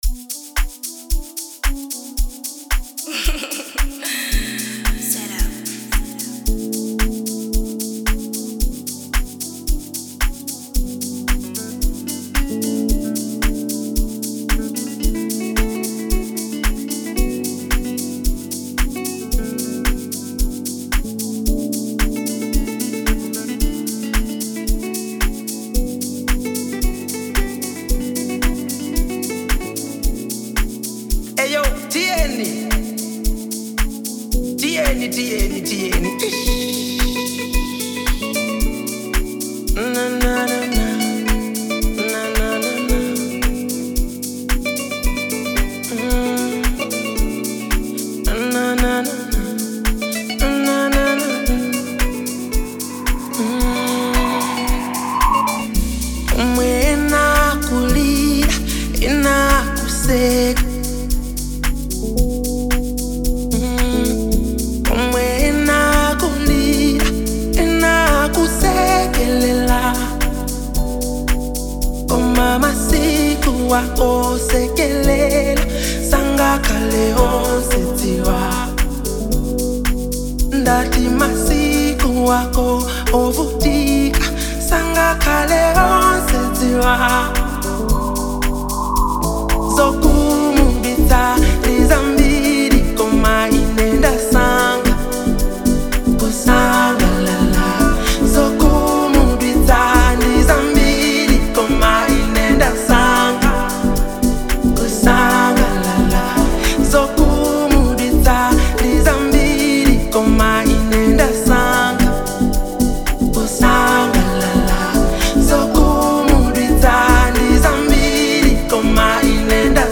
Genre : Amapiano